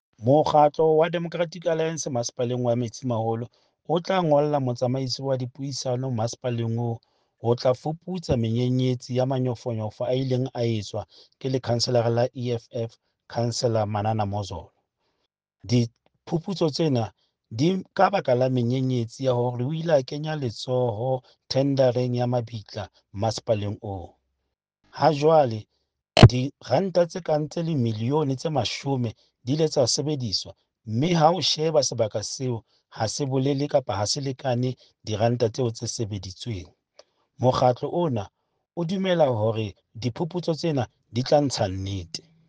Sesotho soundbites by Cllr Stone Makhema and